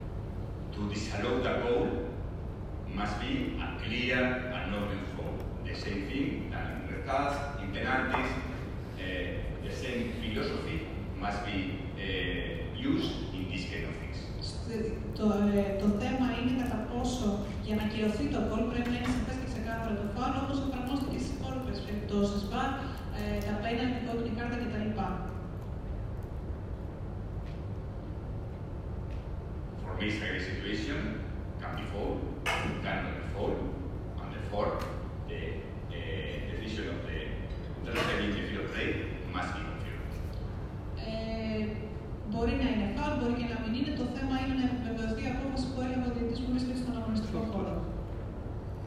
Όπως ακούγεται ξεκάθαρα να λέει στο ηχητικό, και παρά την… λειψή μετάφραση που υπήρξε, αναφέρει χαρακτηριστικά: «There is some possible foul», που σημαίνει ότι «υπάρχει πιθανό φάουλ».
Ακούστε τα δύο ηχητικά του Ισπανού, όπου αναφέρει ξεκάθαρα για την παράβαση του μέσου της ΑΕΚ: